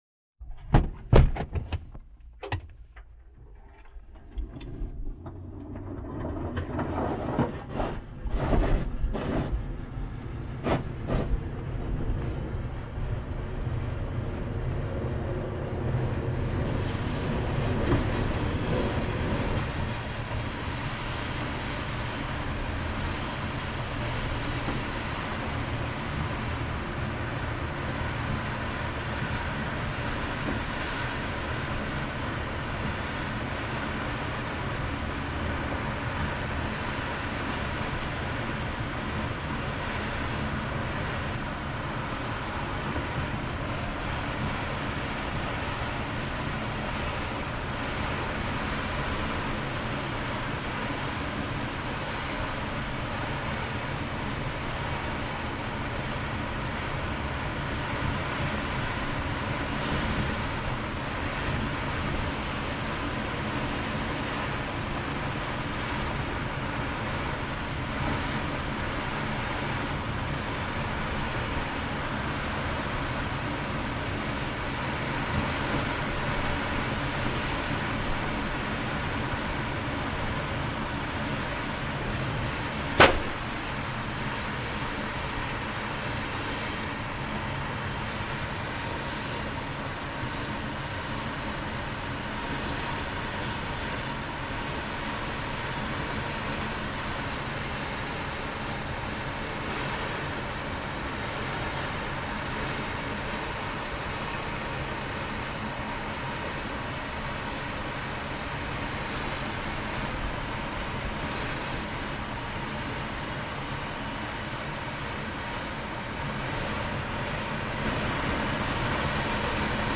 AIRCRAFT PROP GLIDER: INT: Lock canopy, take off, steady flight, landing, wheel chirp, stop.